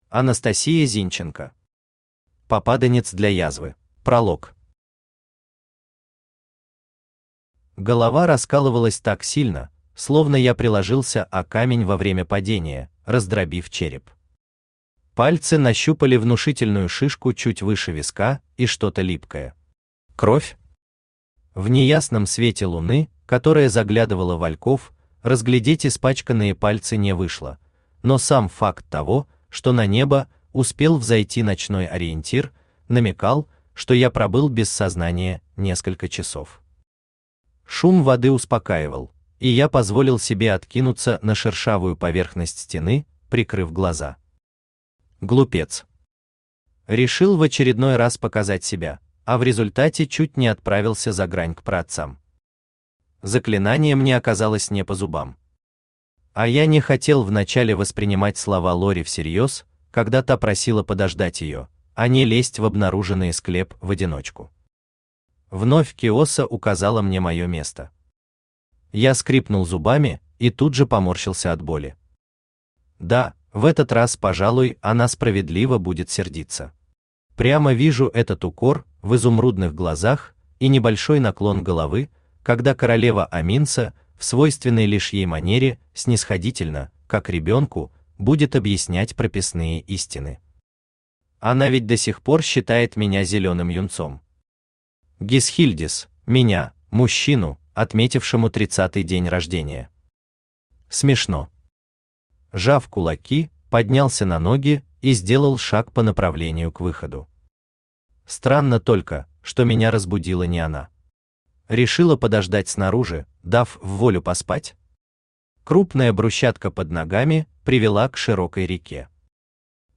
Аудиокнига О, мой лорд! Попаданец для язвы | Библиотека аудиокниг
Попаданец для язвы Автор Анастасия Зинченко Читает аудиокнигу Авточтец ЛитРес.